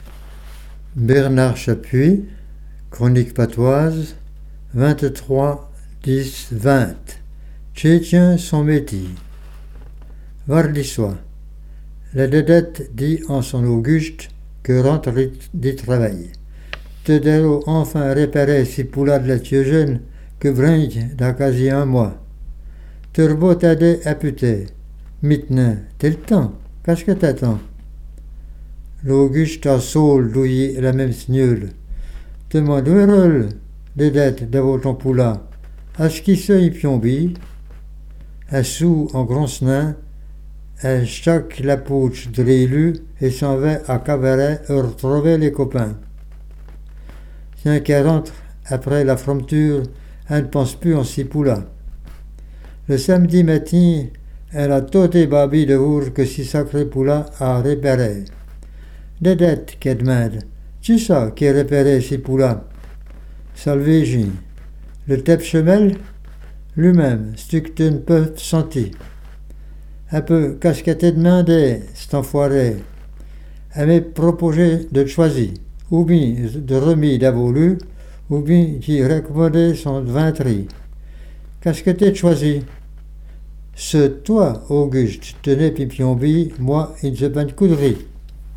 Patois Jurassien